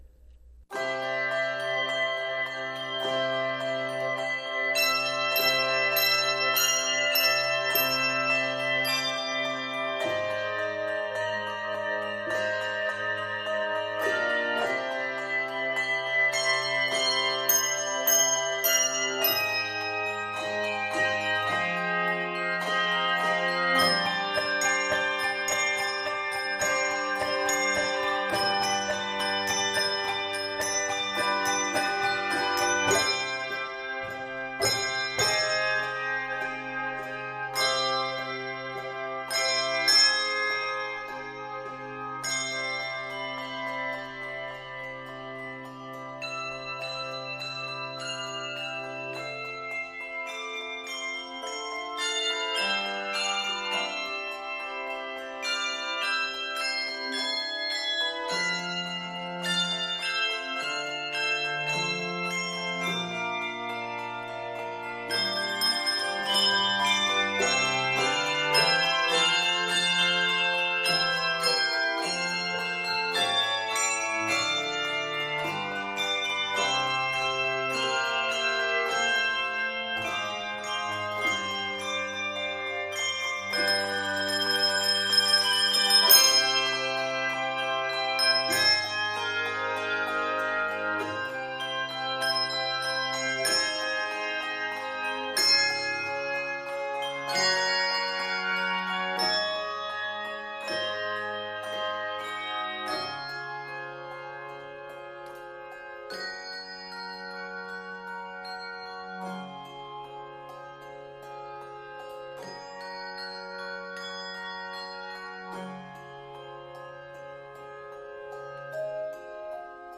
Octaves: 3-6